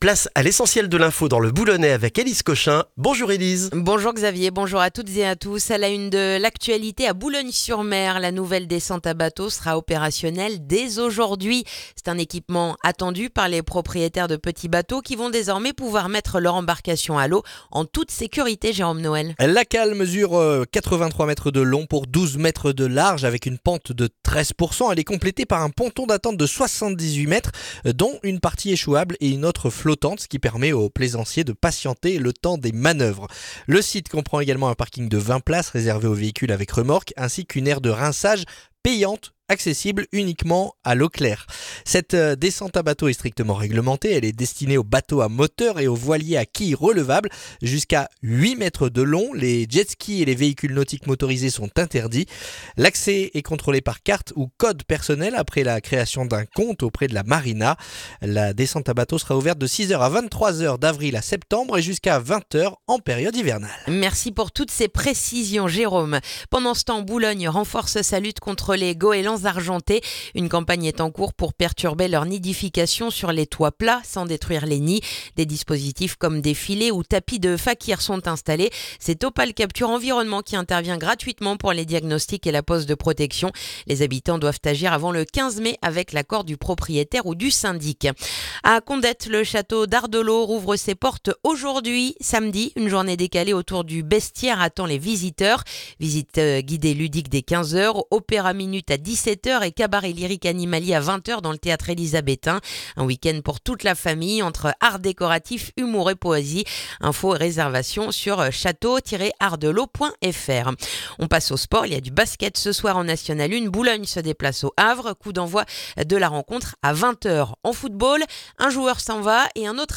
Le journal du mardi 3 février dans le boulonnais